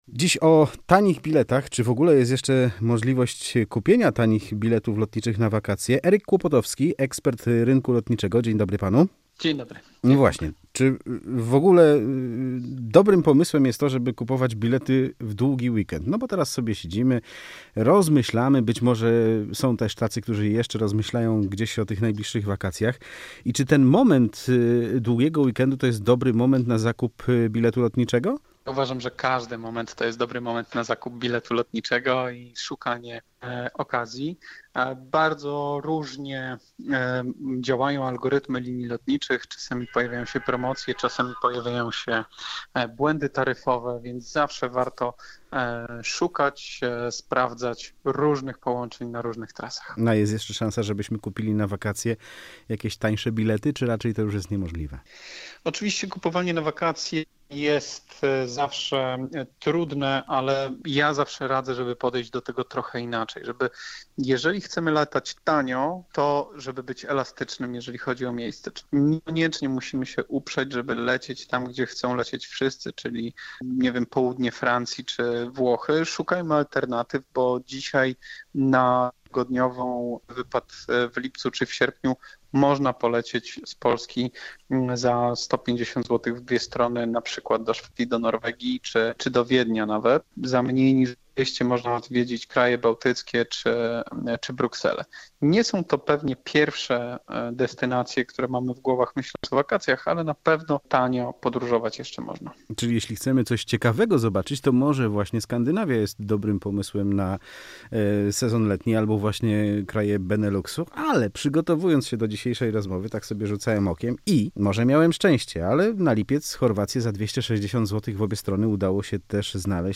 Czy możemy latać tanio w wakacje? Rozmowa z ekspertem rynku lotniczego - Radio Łódź